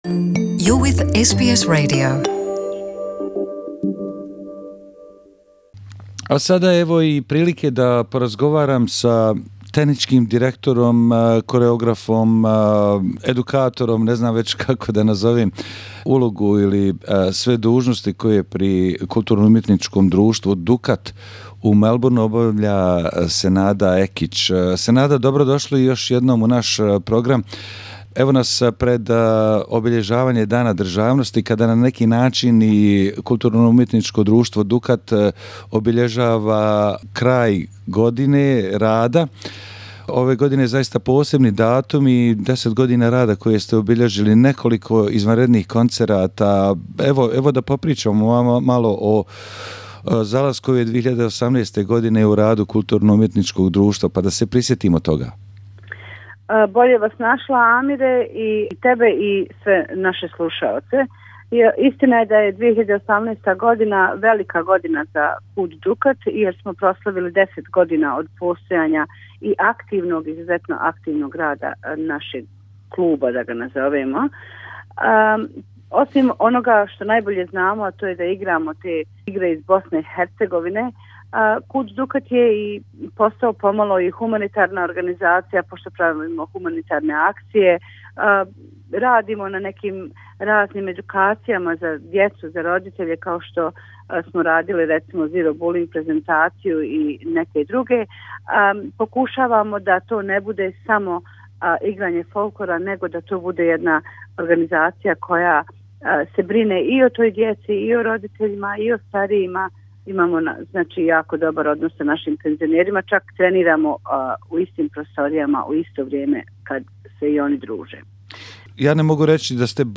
U razgovoru za naš radio